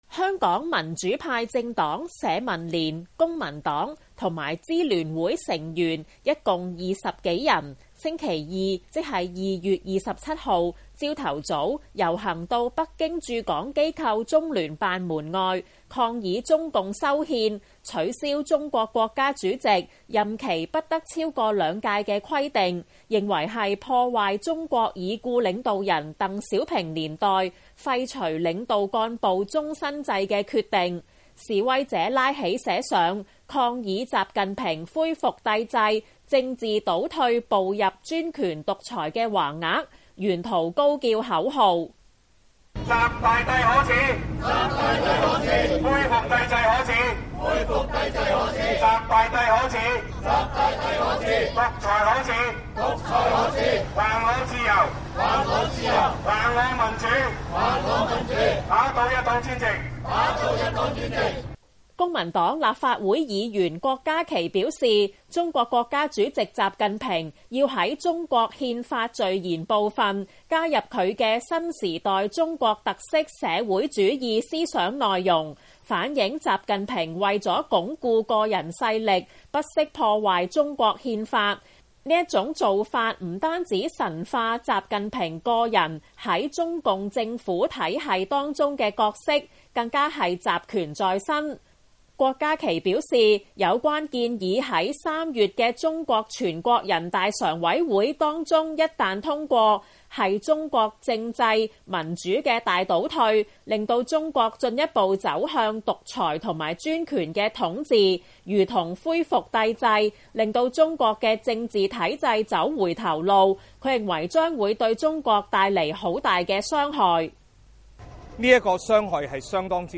示威者拉起寫上「抗議習近平恢復帝制、政治倒退步入專權獨裁」的橫額，沿途高叫口號。
示威者高叫口號：習大帝可恥、恢復帝制可恥﹗習大帝可恥、獨裁可恥﹗還我自由、還我民主﹗打倒一黨專政。